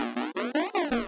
Charamario_DonkeyKongJr_walk1.wav